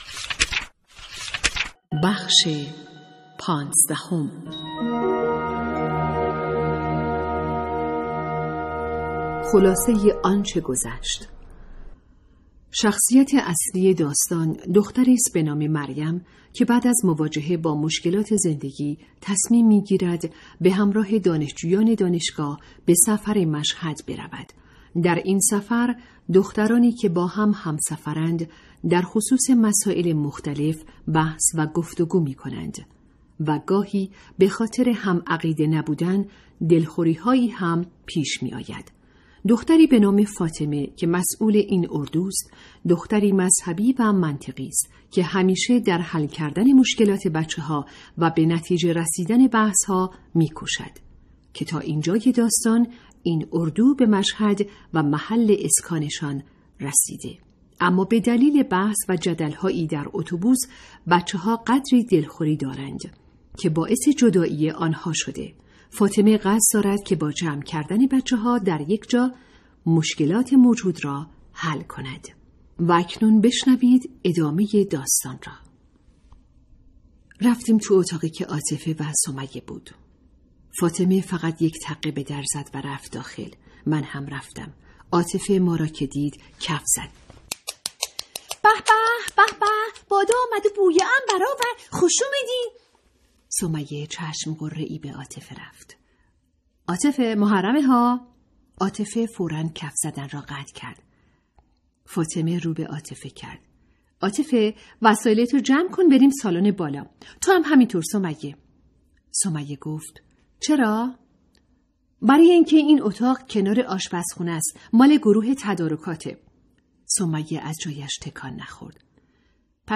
کتاب صوتی | دختران آفتاب (15)